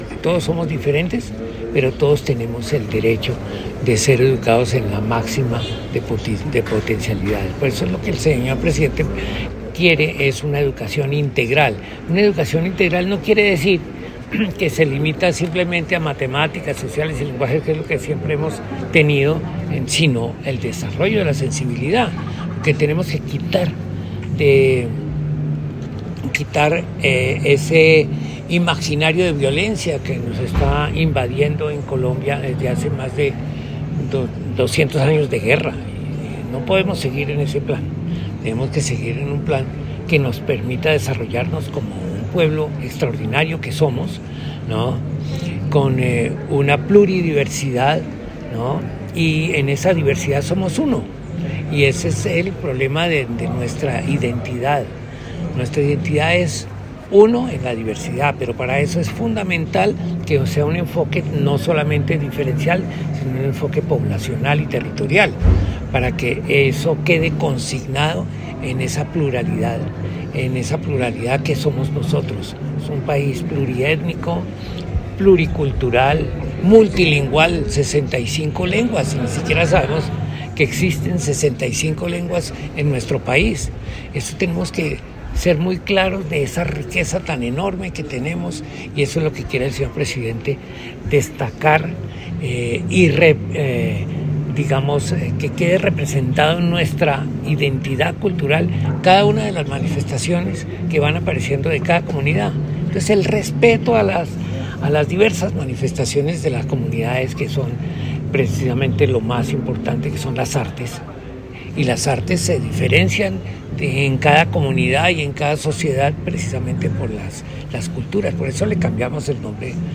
-Audio Viceministro de las Culturas, las Artes y los Saberes, Jorge Ignacio Zorro Sánchez